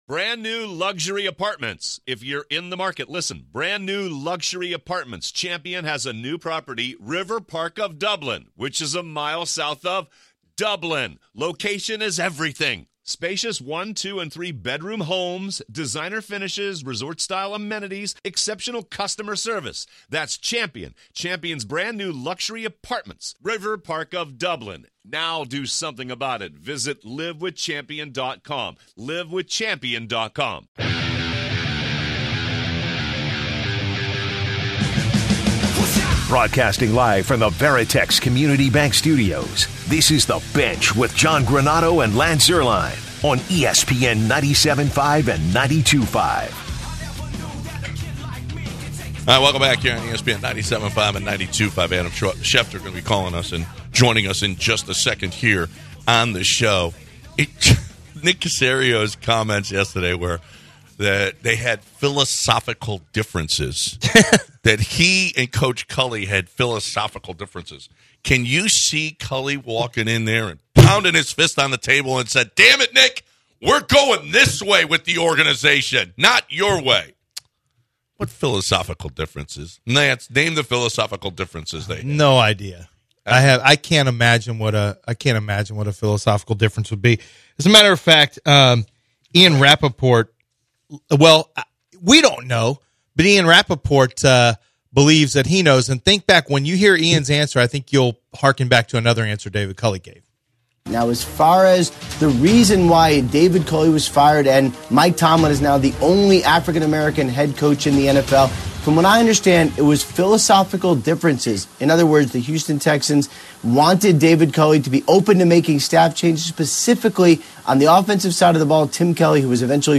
01/14/2022 ESPN Senior NFL Insider Adam Schefter joined The Bench